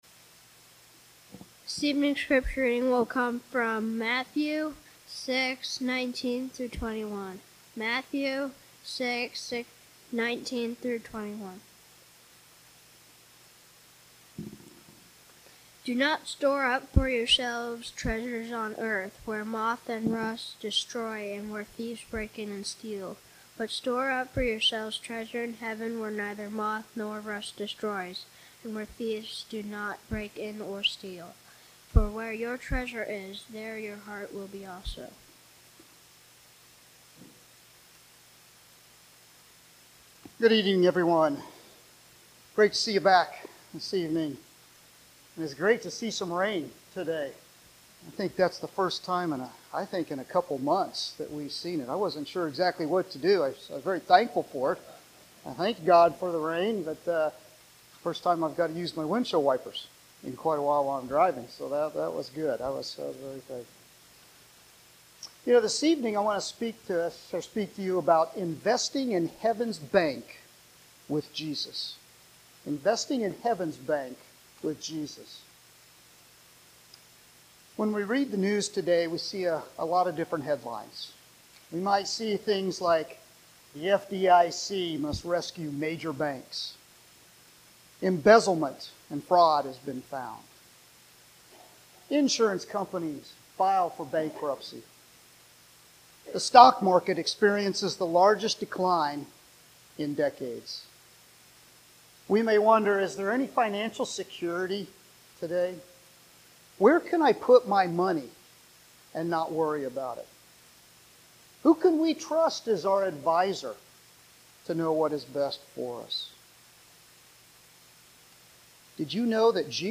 Passage: Matthew 6:19-21 Service: Sunday Evening Topics